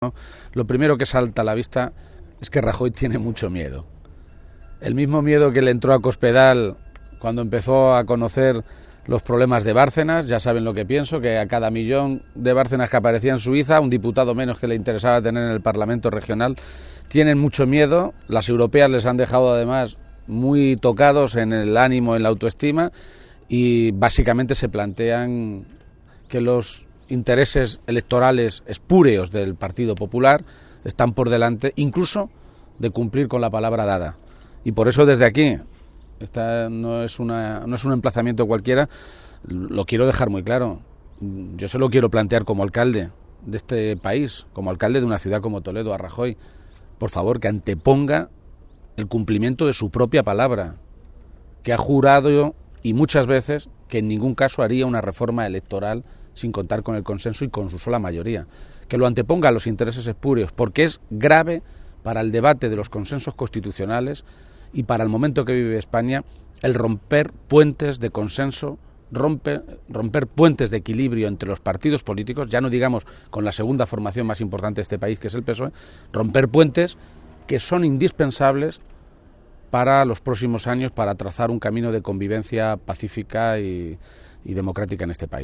García-Page, que realizaba estas declaraciones tras un encuentro con alcaldes y concejales socialistas de la región que se ha celebrado esta mañana en Toledo y en el que también ha estado presente el secretario de Organización del PSOE, César Luena, recordó que Rajoy dijo en 2013 que no llevaría a cabo ninguna reforma de este tipo con la sola mayoría del PP y sin contar con el resto de fuerzas políticas en un asunto de esta importancia.